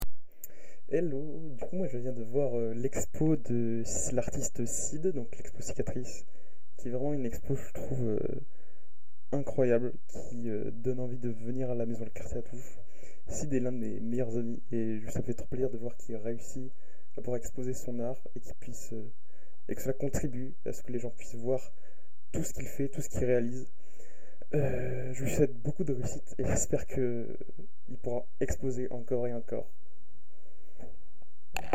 Témoignage enregistré le 6 janvier 2026 à 16h26